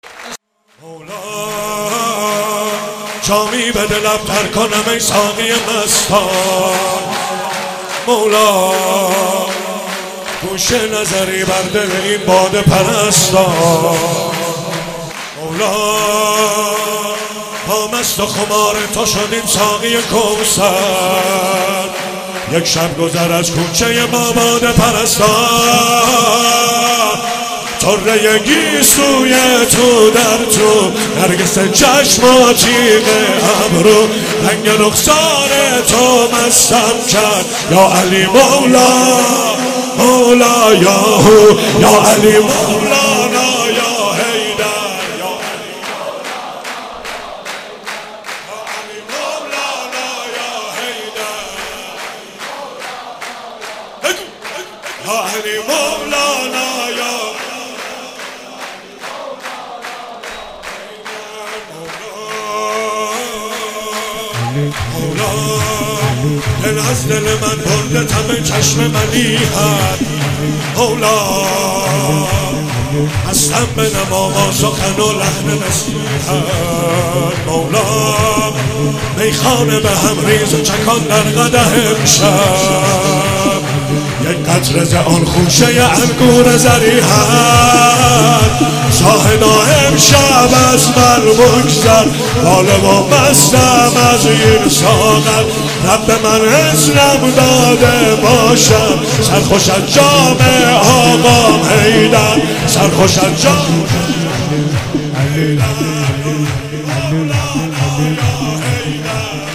عید مبعث
شور مداحی